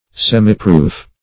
Semiproof \Sem"i*proof`\ (s[e^]m"[i^]*pr[=oo]f`), n.